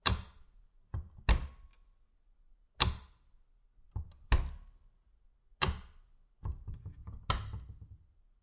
随机 "镶嵌玻璃门的木质橱柜开合点击率4
描述：门木柜与镶嵌玻璃打开close clicks4.flac
Tag: 点击 打开 关闭 玻璃 橱柜 镶嵌